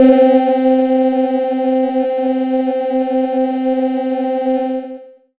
Amb1n2_f_synth_c2_ahhvoice.wav